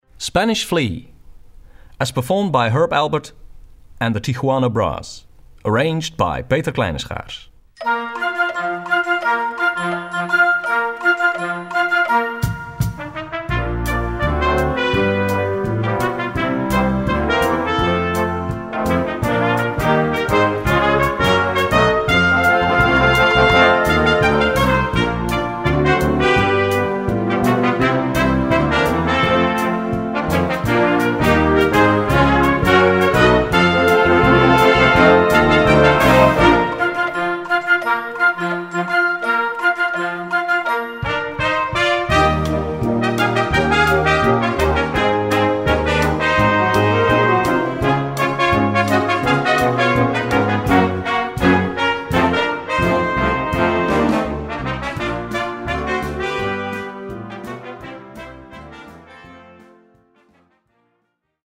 Gattung: Jugendmusik
Besetzung: Blasorchester